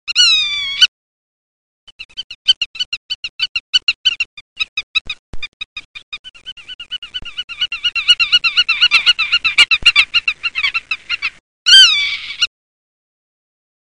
Pittima minore
Limosa lapponica
La pittima è un uccello silenzioso;sembra riservi modeste emissioni vocali al mantenimento dei contatti all’interno dello stormo migratorio.